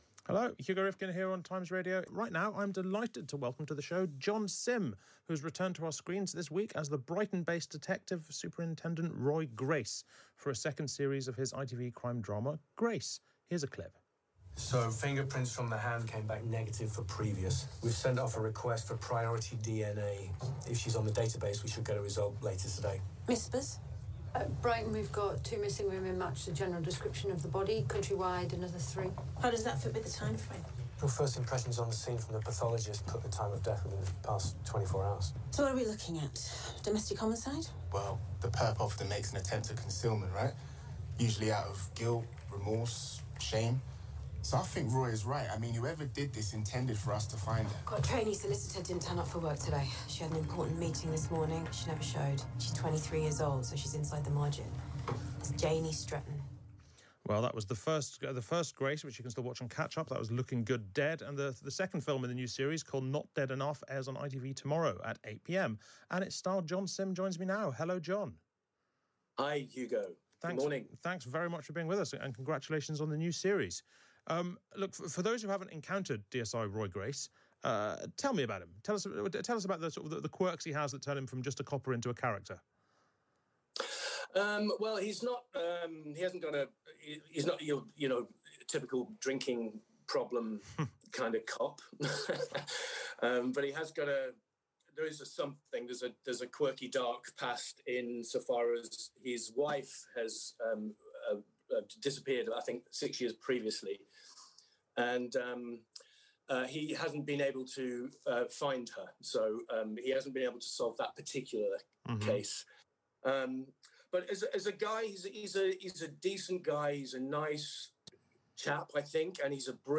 Radio Interview: Hugo Rifkind chats with John Simm about the new series of ‘Grace’.